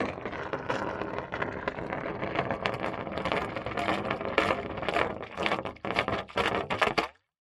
Звуки водоворота